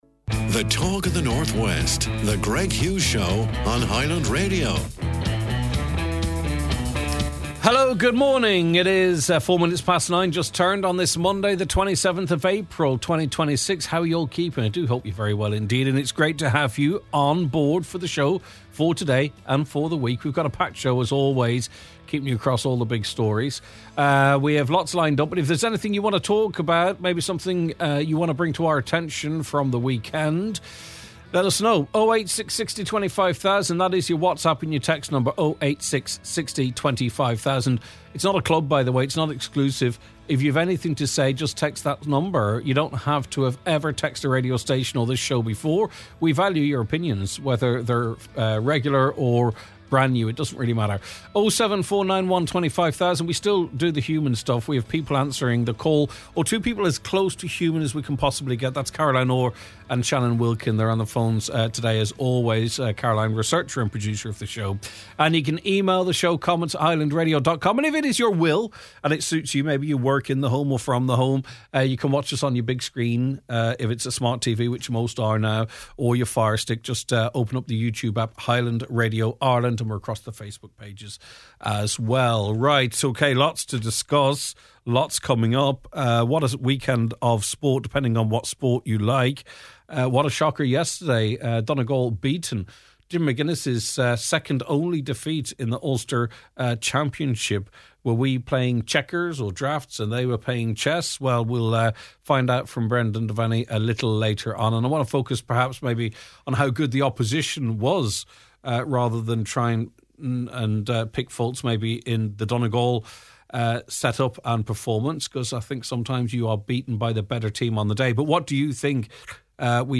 We hear listener reactions to the performance.